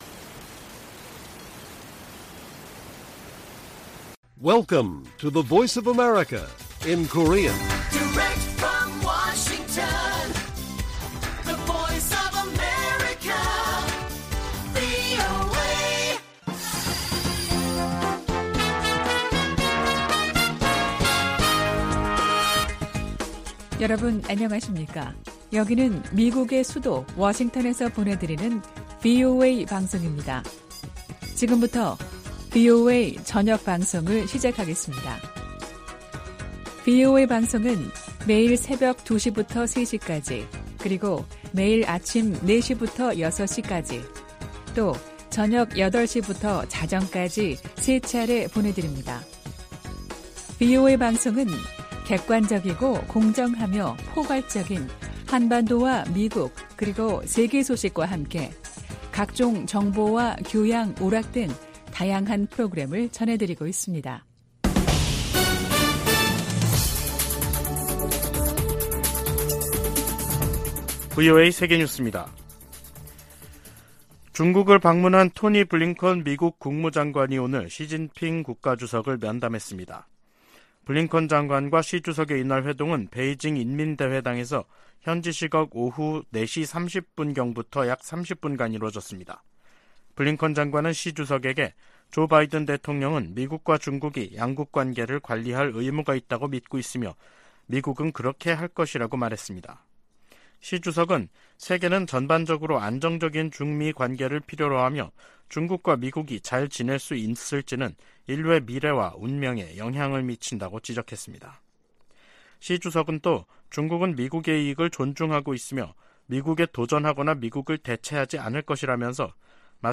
VOA 한국어 간판 뉴스 프로그램 '뉴스 투데이', 2023년 6월 19일 1부 방송입니다. 미국의 핵 추진 순항미사일 잠수함의 한국 입항과 관련해 미국 내 전문가들은 방위 공약을 확인하는 조치로 해석했습니다. 북한은 노동당 전원회의에서 군사정찰위성 발사 실패를 가장 엄중한 결함으로 지목하고 이른 시일 내 재발사하겠다고 밝혔습니다. 미 하원의장이 주한 중국대사의 '베팅 발언'에 대해 잘못된 것이라고 지적했습니다.